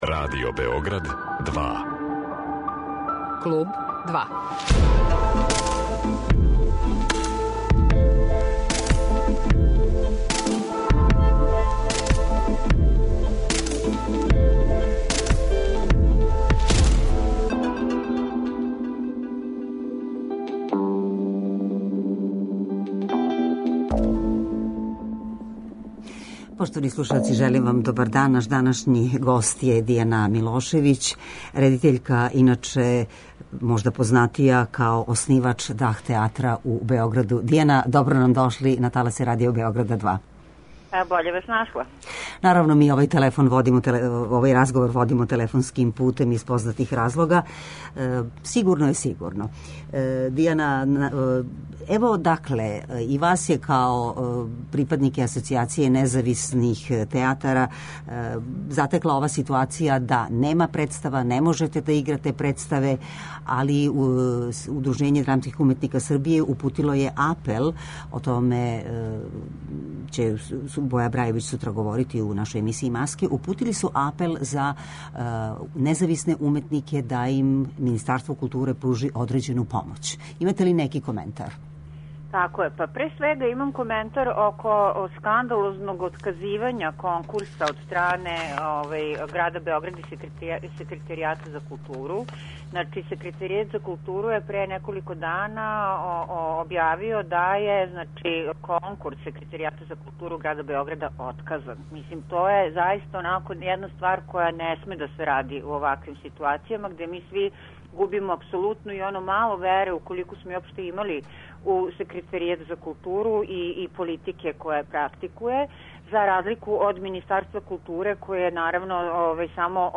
Разговор водимо уживо телефоном.